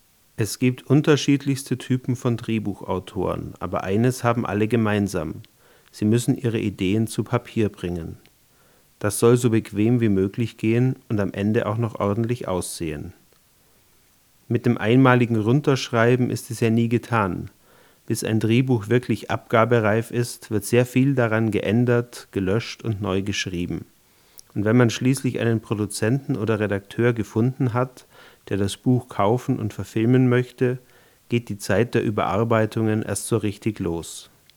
Sprachaufnahmen - Bitte um Beurteilung
Alle wurden hier in meinem Wohnzimmer (Dachwohnung mit Teppichboden) aus ca. 20 cm Abstand gemacht und anschließend in Audacity normalisiert; weitere Bearbeitung steckt noch nicht drin.